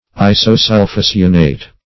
Search Result for " isosulphocyanate" : The Collaborative International Dictionary of English v.0.48: Isosulphocyanate \I`so*sul`pho*cy"a*nate\, n. (Chem.)
isosulphocyanate.mp3